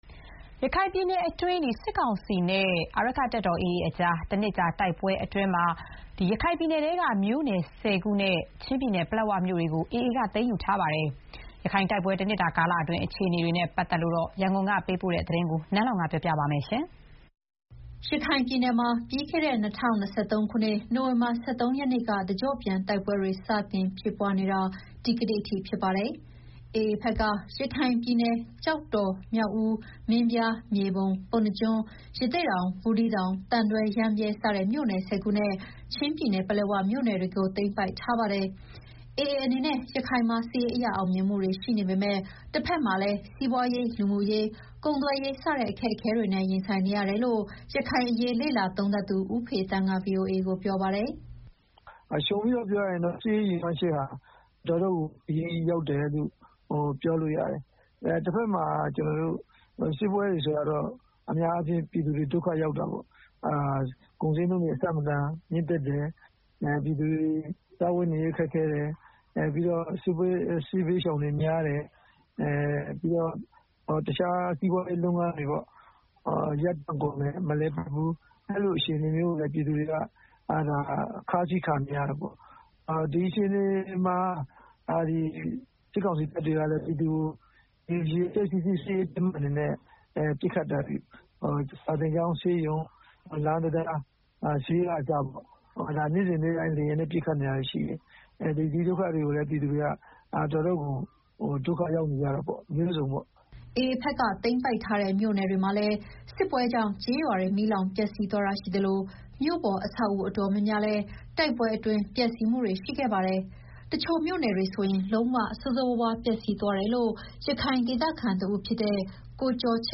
ရခိုင်ပြည်နယ်တွင်း စစ်ကောင်စီနဲ့ အာရက္ခတပ်တော် AA အကြား တနှစ်ကြာတိုက်ပွဲအတွင်း ရခိုင်ပြည်နယ်တွင်း မြို့နယ် ၁၀ခုနဲ့ ချင်းပြည်နယ် ပလက်ဝမြို့တွေကို AA က သိမ်းယူထားပါတယ်။ ရခိုင်တိုက်ပွဲ တနှစ်တာကာလအတွင်း အခြေအနေတွေနဲ့ ပတ်သတ်ပြီး ရန်ကုန်က သတင်းပေးပို့ထားတာကို ပြောပြမှာပါ။